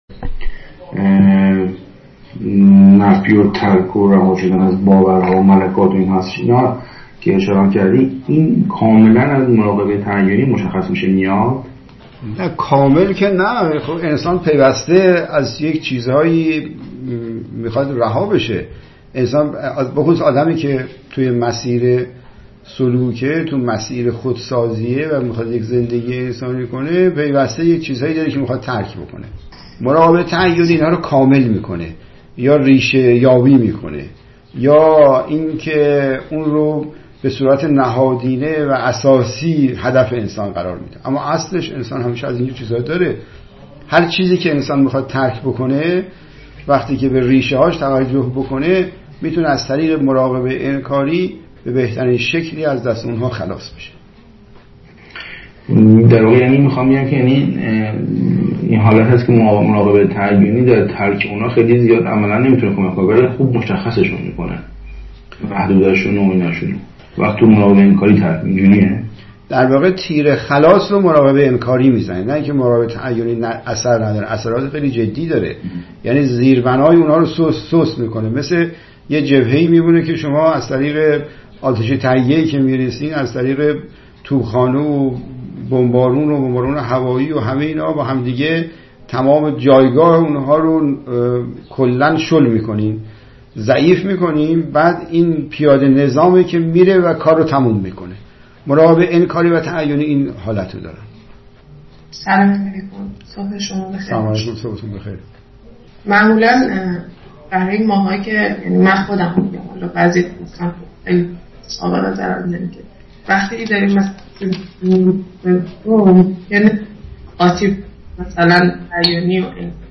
متن : گفت‌وگو